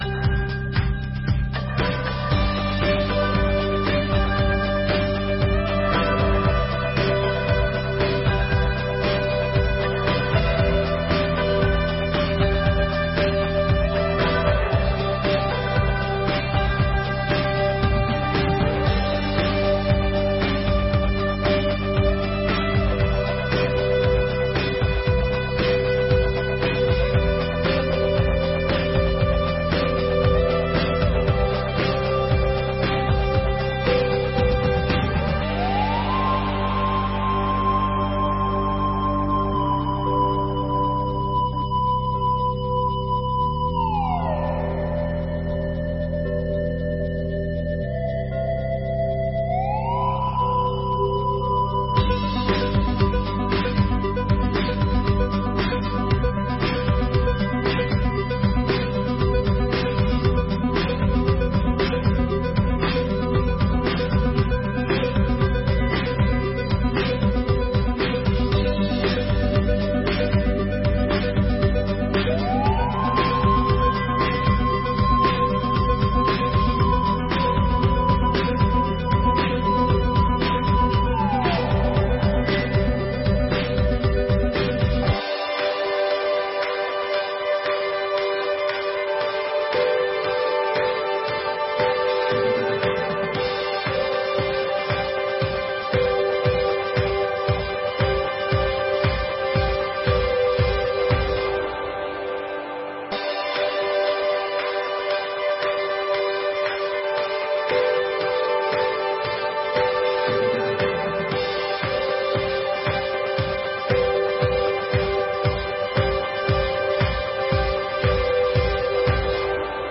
24ª Sessão Ordinária de 2024 — Câmara Municipal de Garça